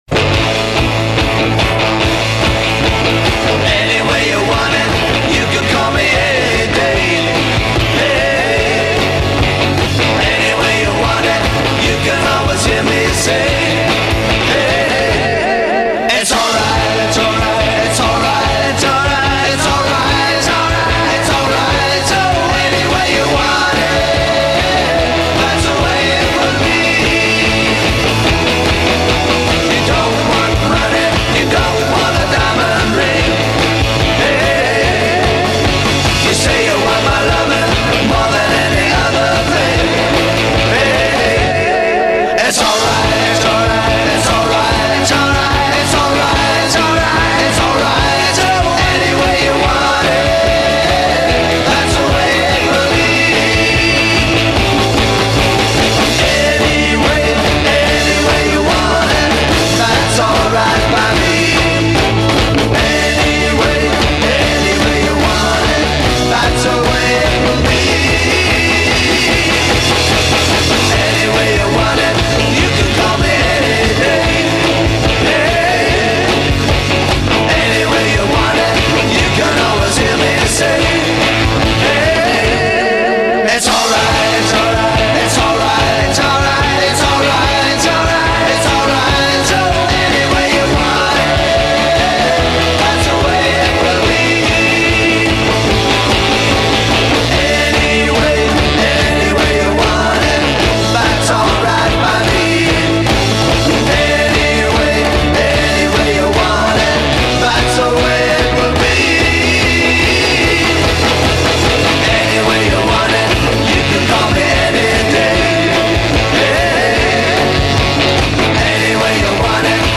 бит
поп-рок